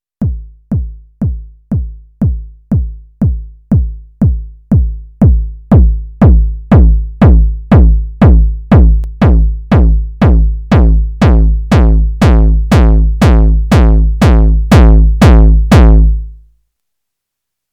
Here’s the BD HARD on the Syntakt in default settings, I then add Overdrive, and then the FX Drive, until everything is maxed out.